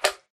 mob / slime1